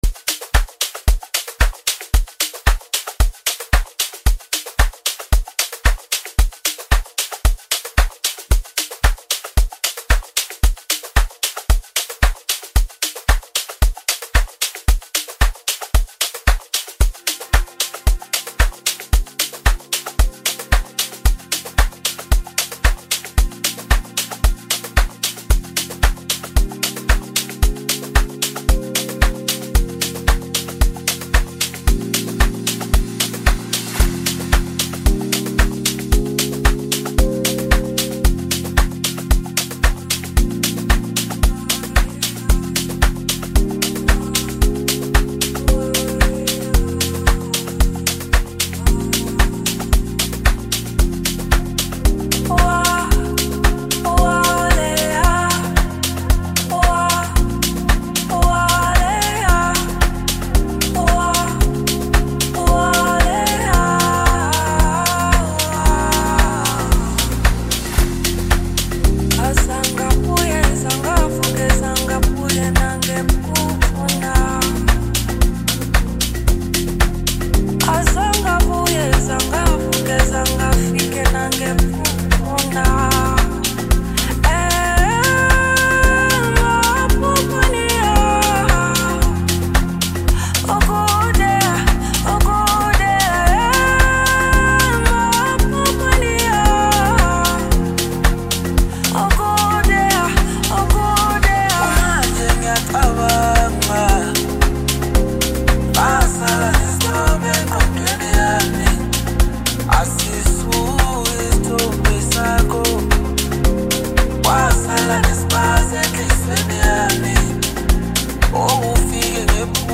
Home » Amapiano » DJ Mix » Lekompo
South African singer-songsmith